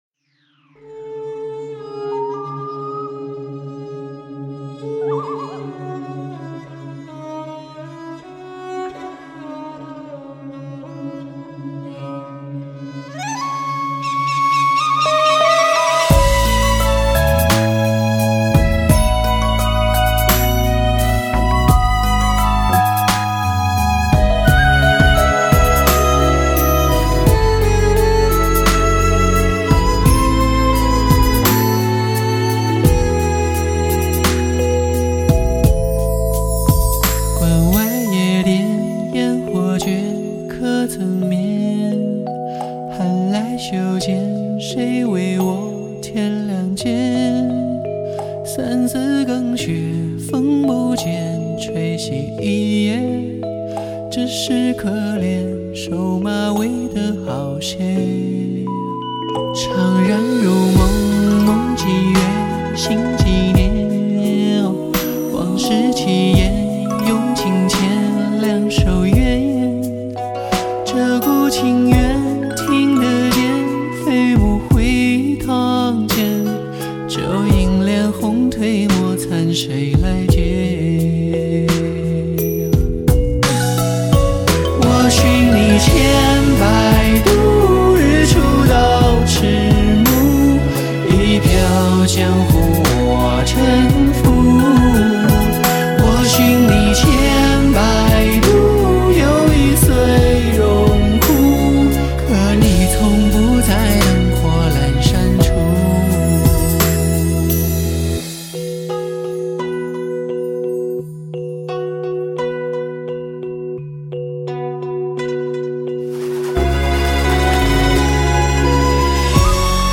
温婉内敛与开阔大格局兼容并蓄